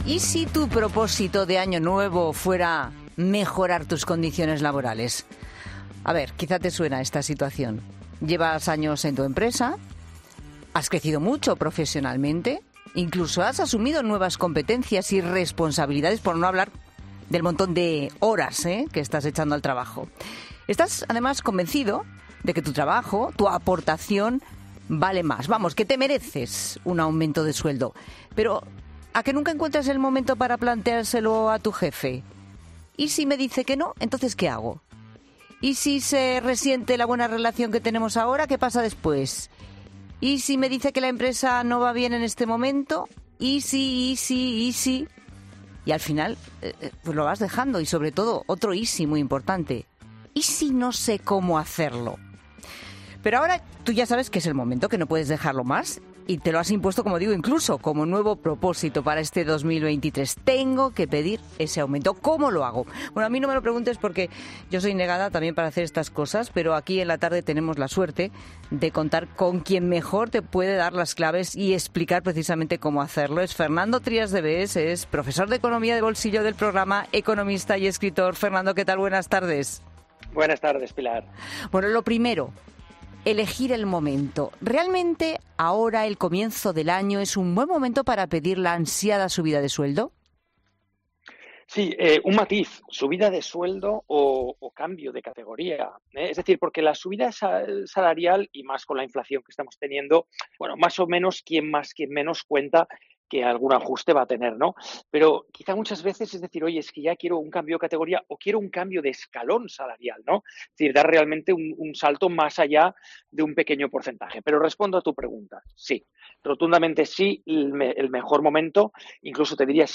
El economista Trías de Bes analiza en La Tarde cuándo es el mejor momento para pedir una subida salarial y cómo negociarlo